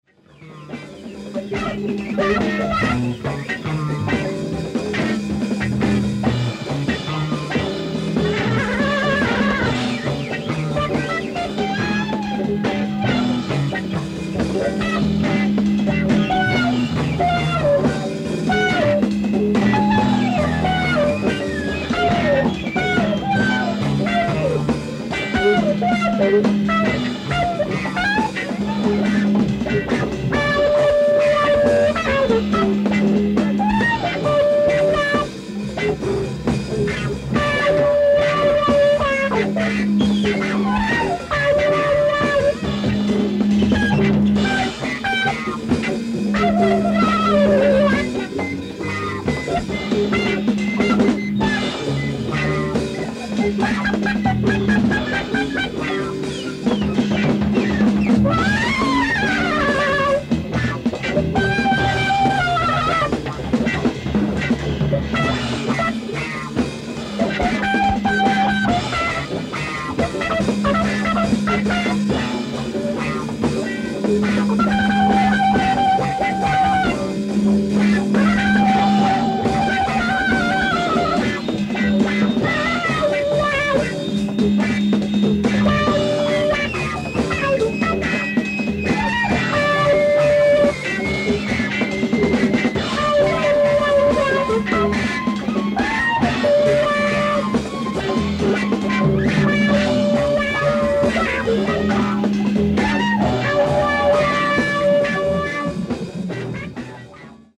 ディスク１：ライブ・アット・ポールズ・モール、ボストン 09/14/1972
海外マニアによるレストア、ノイスレス盤！！
※試聴用に実際より音質を落としています。
Disc 1(soundboard recording)